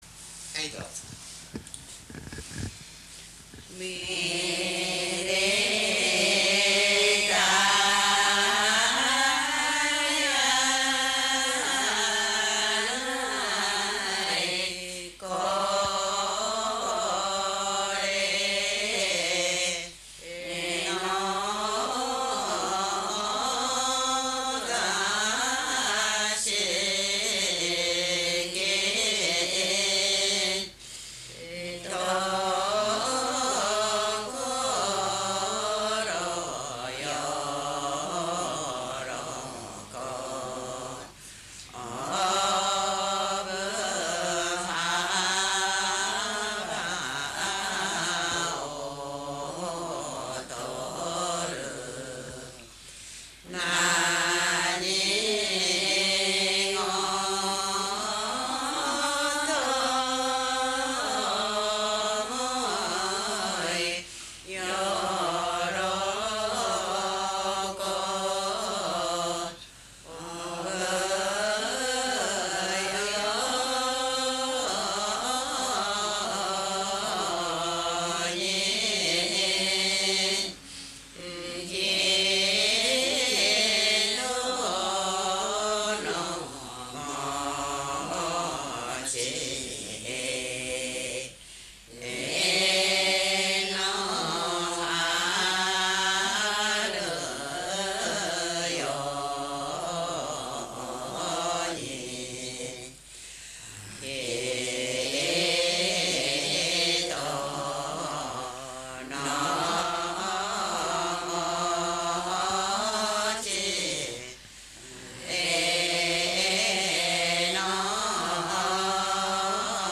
2 2 9 船橋市 　 飯山満町
はっせ 祝い歌